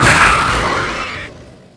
c_alien_atk3.wav